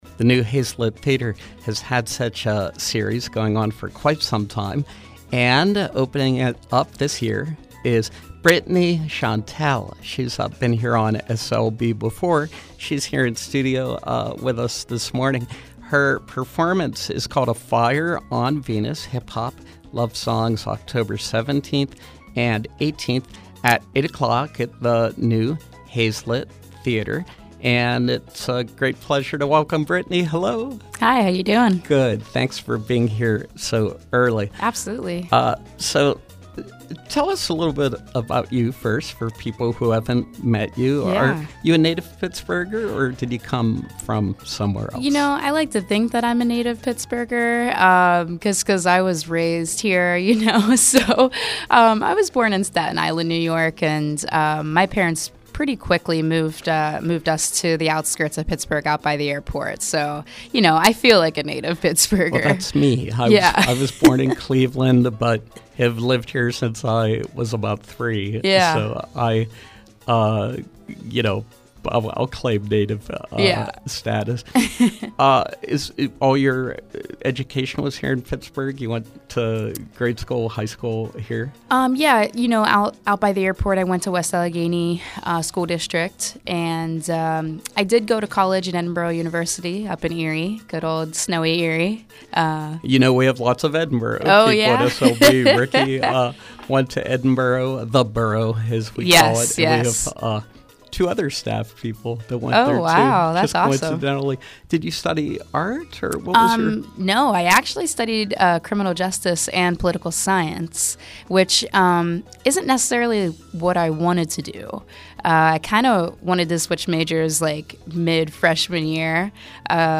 In-Studio Pop Up
Interviews